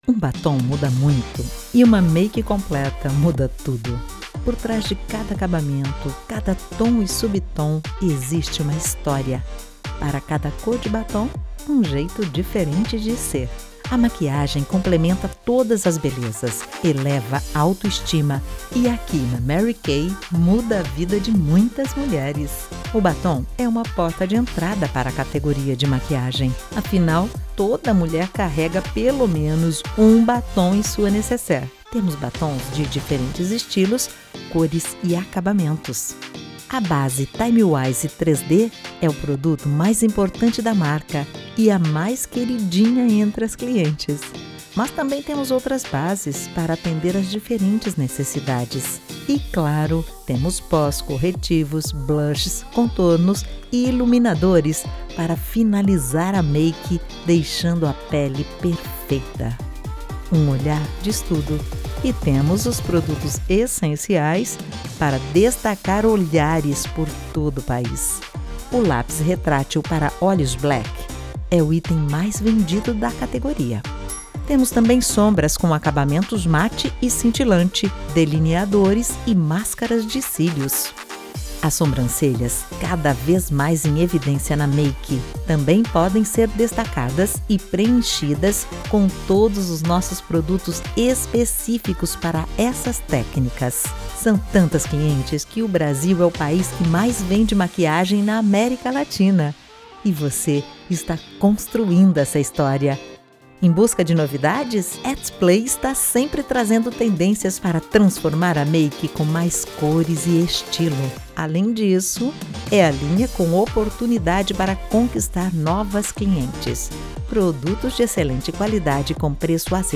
Institucional /Mary Kay - 2021
Voz Padrão - Grave 02:32
Owns an at home recording studio.